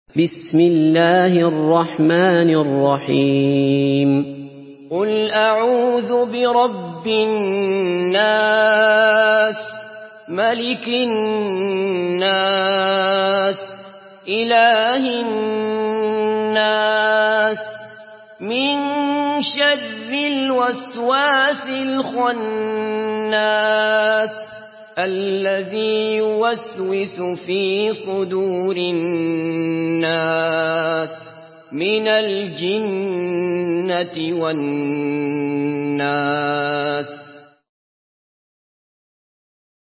Surah An-Nas MP3 in the Voice of Abdullah Basfar in Hafs Narration
Murattal Hafs An Asim